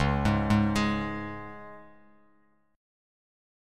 Db5 Chord
Listen to Db5 strummed